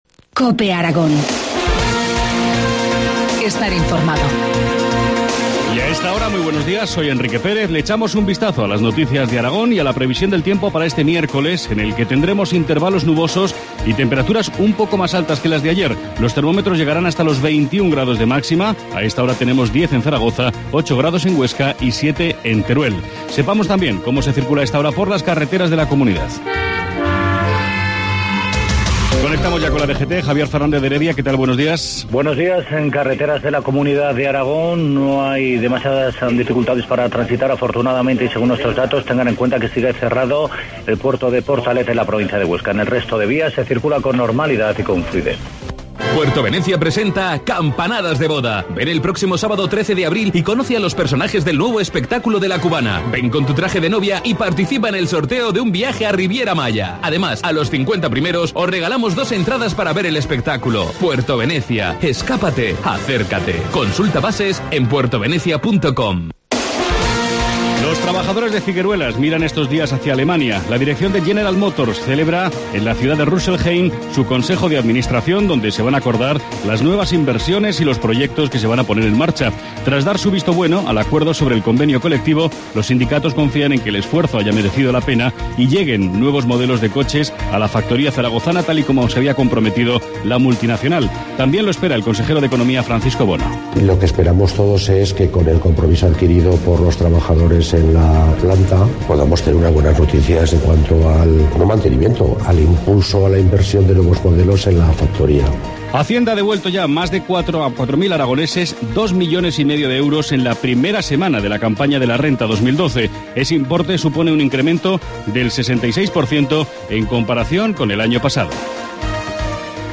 Informativo matinal, miércoles 10 de abril, 7.25 horas